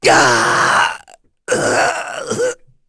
Baudouin-Vox_Dead.wav